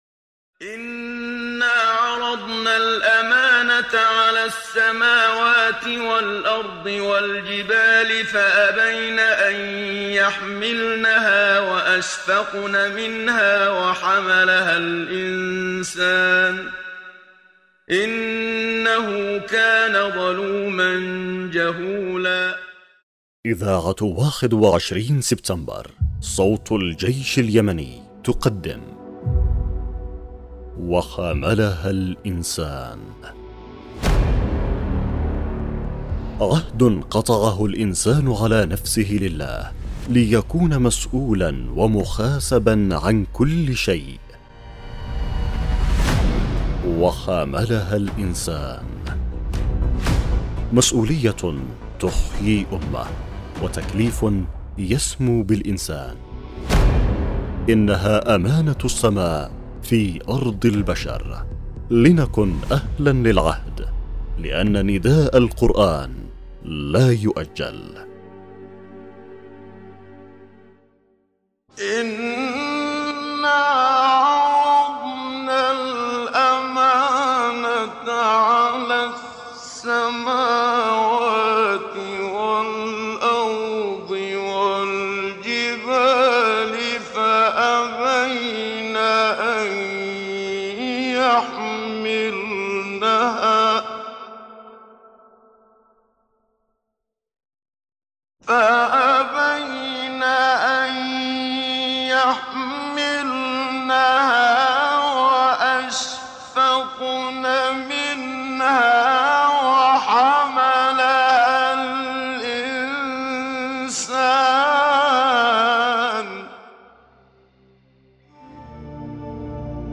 الحلقة (2) برنامج إذاعي يعمل على مناقشة قضايا المسؤولية تجاه كل شيء المسؤولية تجاه الاسرة والأولاد وتجاه الدين والإسلام وتجاه المقدسات وتجاه الشهداء وكل قضية الانسان مسؤول عليها امام الله مع شرح ونقاش عن كل الجوانب التي تشملها تلك المسؤولية